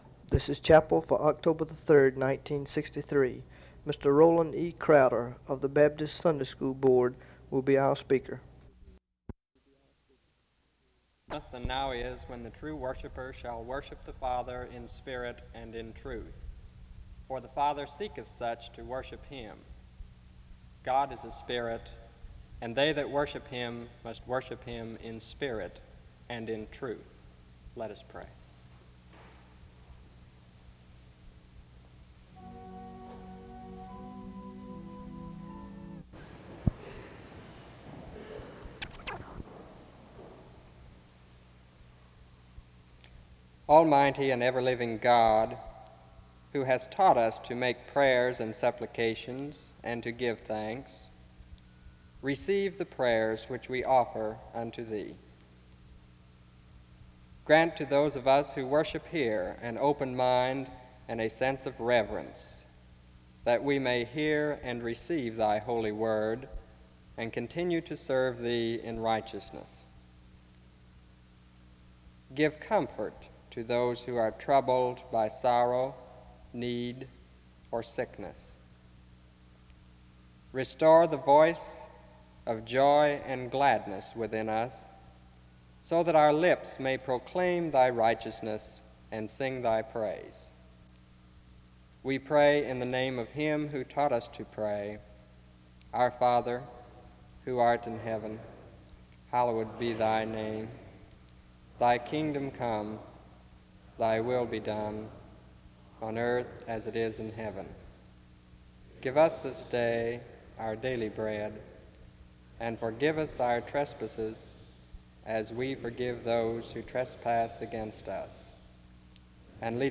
The service begins with scripture reading and prayer from 0:00-2:11. An introduction to the speaker is done from 2:18-2:57.